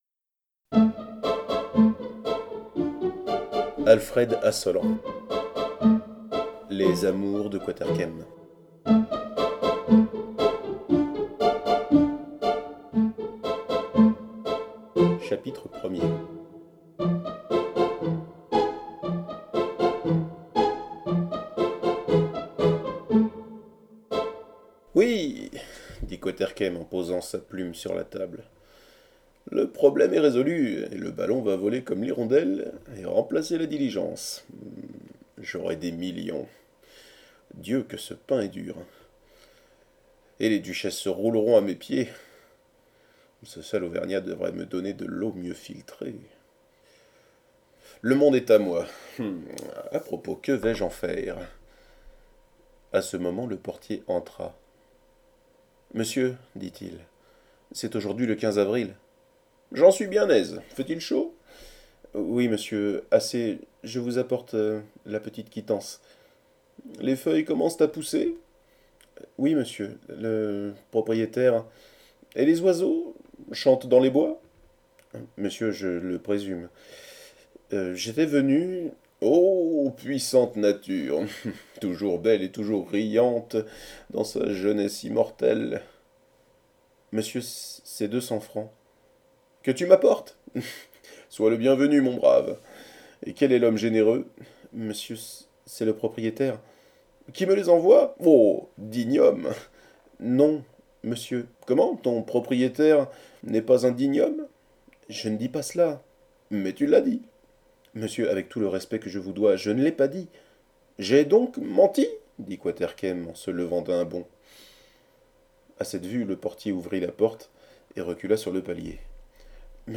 ASSOLLANT Alfred – Livres Audio !